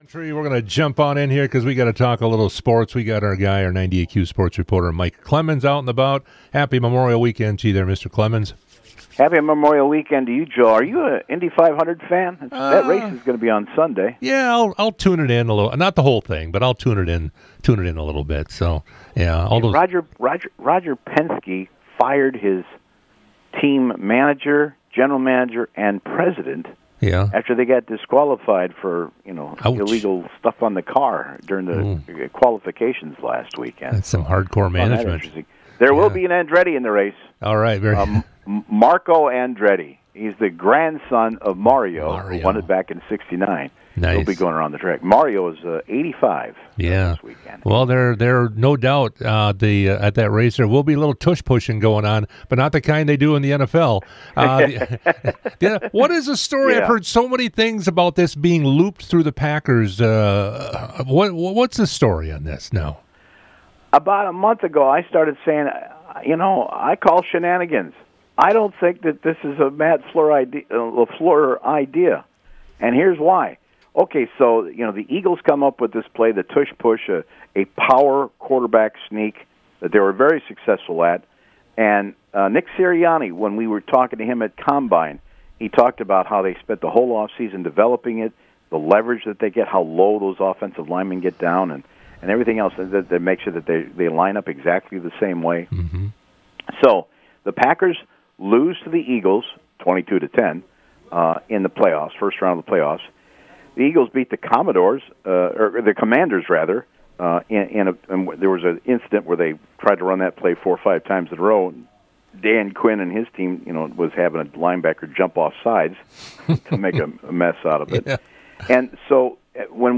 98q interviews